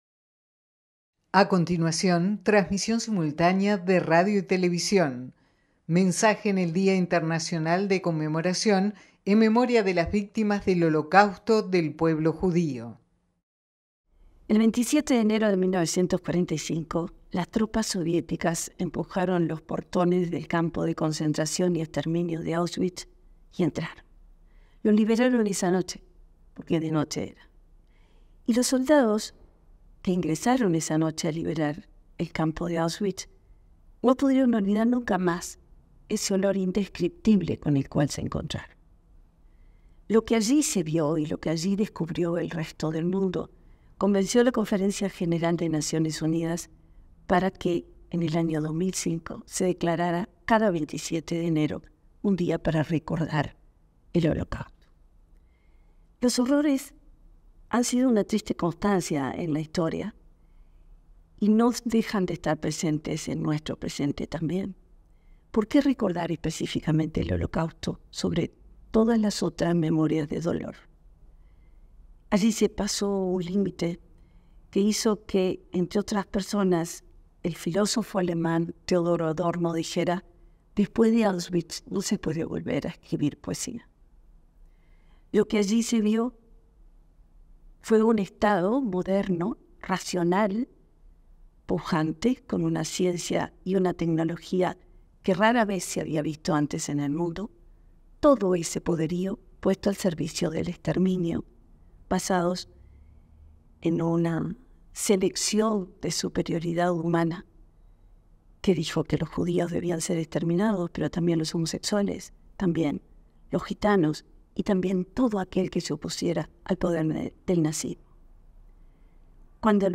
En el Día Internacional de Conmemoración en Memoria de las Víctimas del Holocausto, se expresó la subsecretaria de Educación y Cultura, Ana Ribeiro.